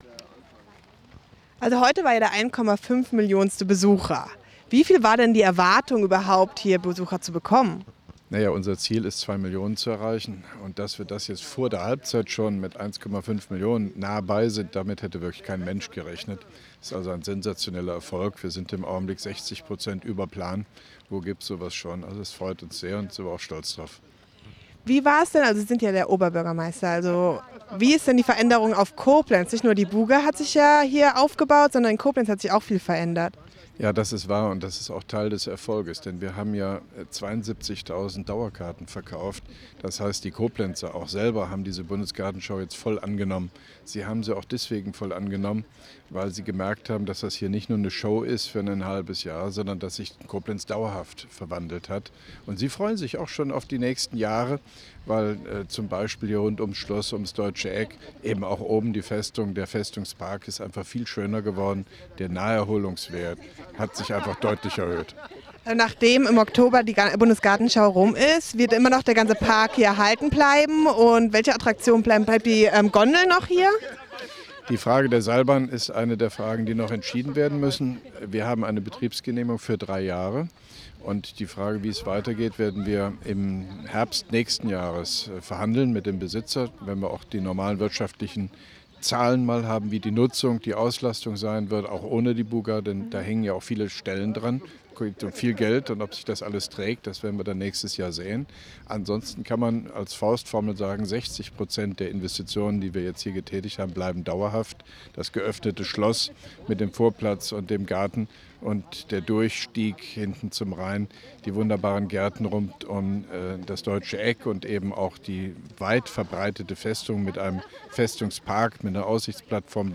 Mit Interview des Koblenzer OB Hofmann-Göttig